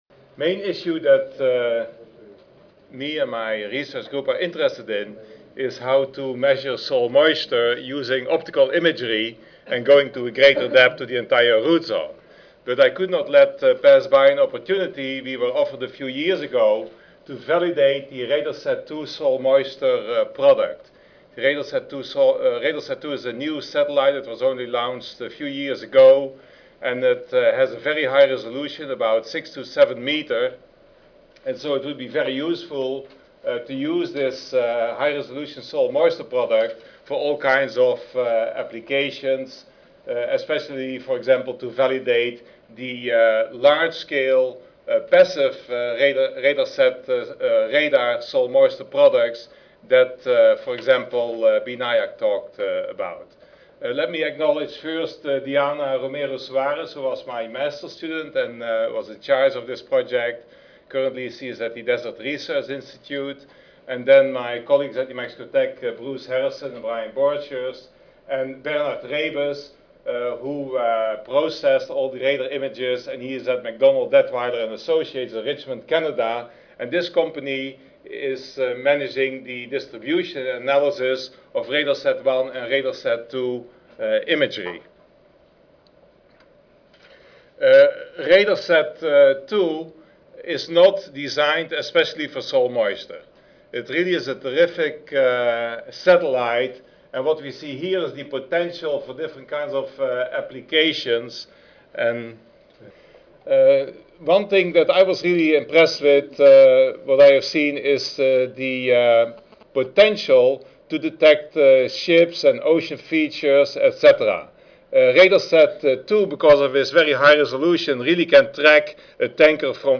S01 Soil Physics Session: Soil Change: Characterization and Modeling Across Scales: I (2010 Annual Meeting (Oct. 31 - Nov. 3, 2010))
MDA Corporation Audio File Recorded presentation